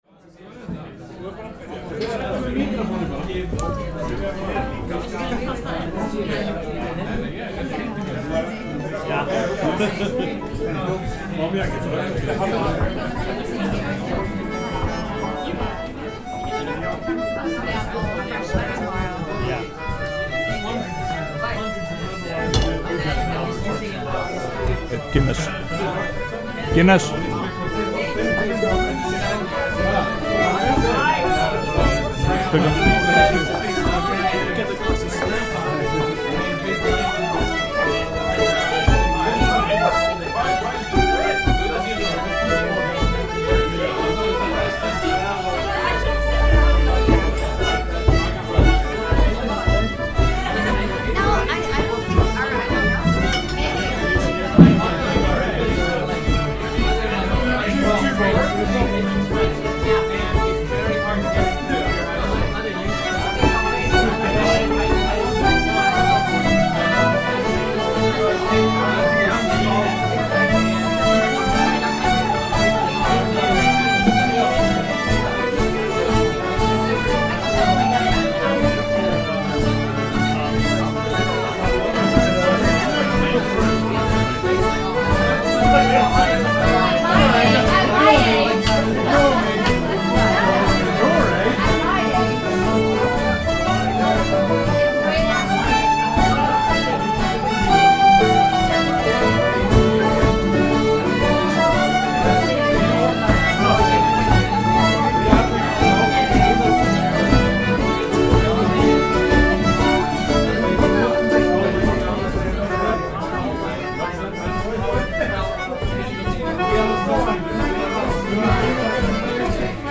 Posted in Music & release, tagged Binaural recording, Hljóðfæraleikur, Korg MR1000, Lækjargata 10, Music, Söngur, Sennheiser MKE 2, Sound device 302, Tónlist on 16.9.2010| 4 Comments »
Hljóðfæraleikarar fjölmenntu á efstu hæð á Highlander á Lækjargötu 10, þann 15. júlí 2010.
En þegar fór að líða á kvöldið þá duttu menn í gírinn og þá birtust söngvarar, einn innlendur sem áður hefur sungið með þessum hópi og tveir erlendir ferðamenn sem óvart voru á staðnum. Heyra má bluegrass, keltneska og skandinavíska tónlist að þessu sinni. Sá hluti upptökunnar sem hér heyrist er frá seinni hluta kvöldins þegar menn voru komnir í gírinn og söngvarar voru farnir að þenja sig.